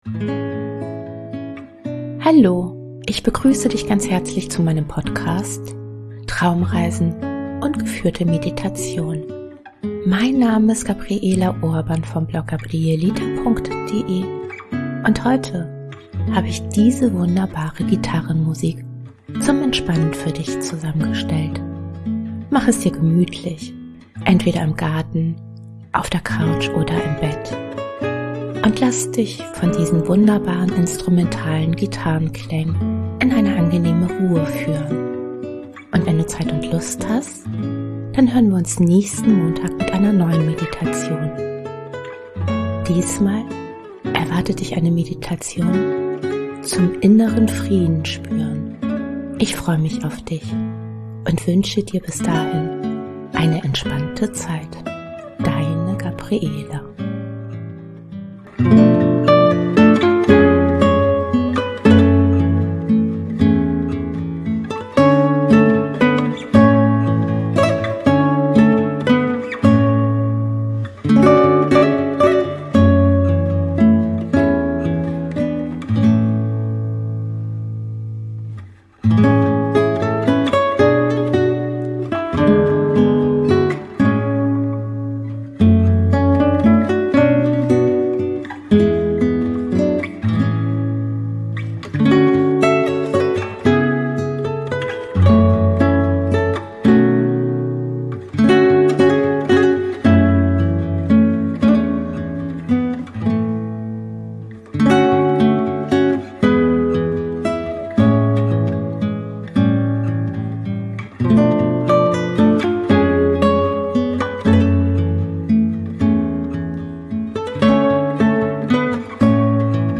Willkommen zur kurzen Meditation Energie und Kraft.